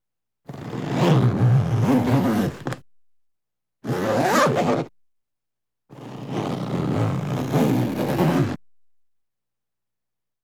Cloth Zipper Sound
household
Cloth Zipper